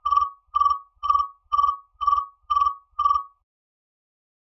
Disturbing ding ding
chime ding dong ring sound effect free sound royalty free Sound Effects